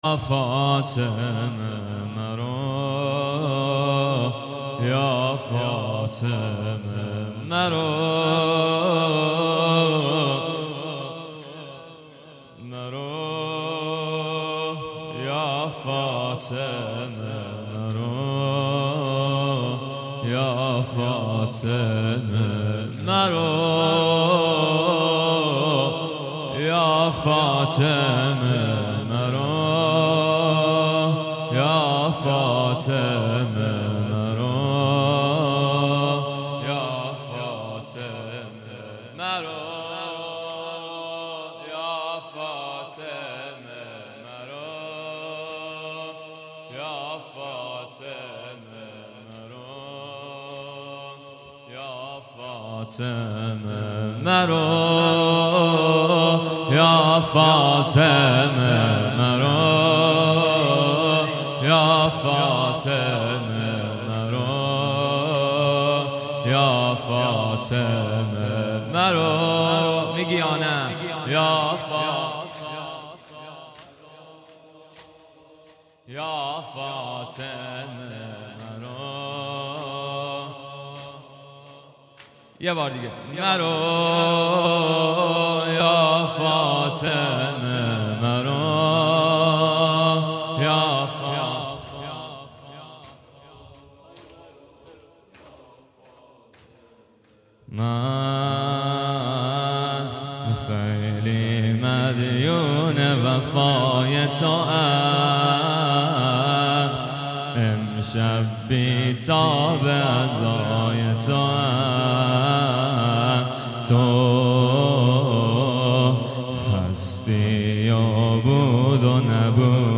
زمینه)1.mp3